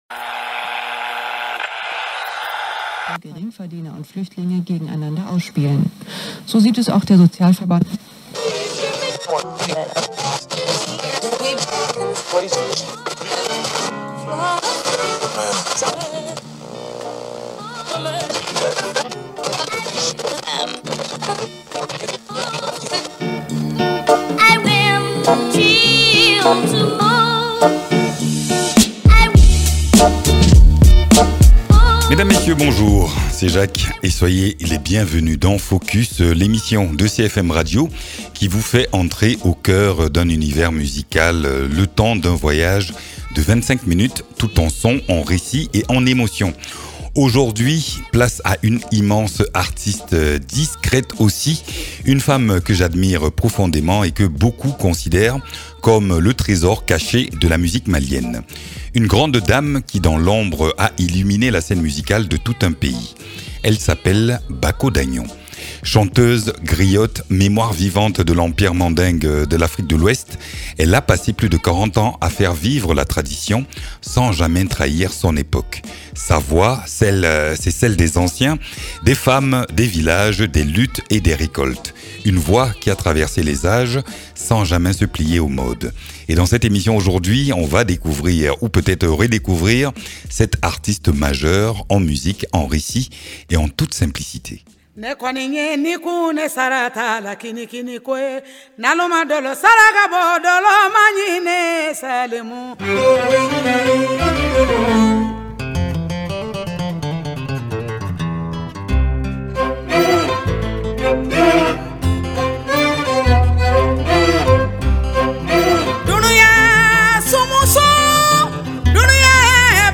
Une voix précieuse, rare, qui résonne encore bien au-delà des frontières du Mandé.